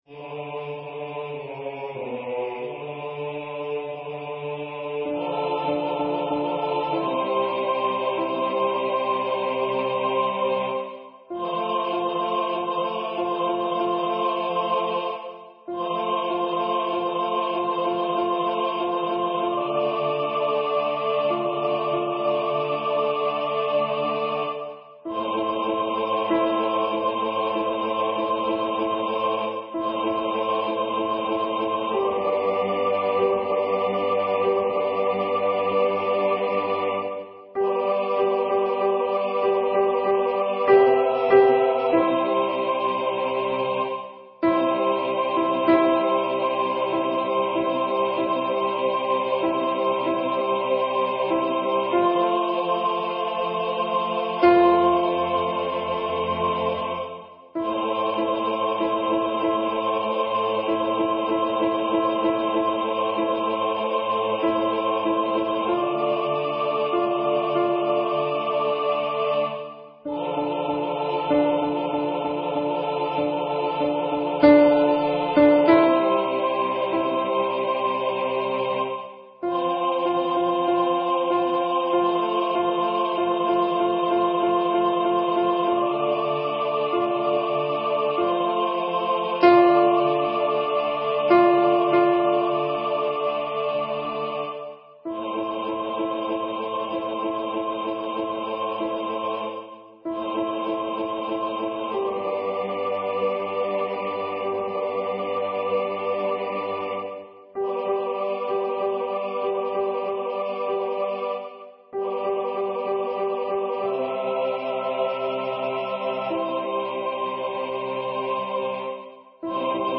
Number of voices: 4vv   Voicing: SATB
Genre: SacredMass